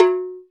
Index of /90_sSampleCDs/NorthStar - Global Instruments VOL-2/CMB_CwBell+Agogo/CMB_CwBell+Agogo